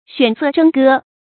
选色征歌 xuǎn sè zhēng gē
选色征歌发音
成语注音 ㄒㄨㄢˇ ㄙㄜˋ ㄓㄥ ㄍㄜ